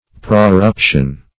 Search Result for " proruption" : The Collaborative International Dictionary of English v.0.48: Proruption \Pro*rup"tion\, n. [L. proruptio, fr. prorumpere, proruptum, to break forth; pro forth + rumpere to break.] The act or state of bursting forth; a bursting out.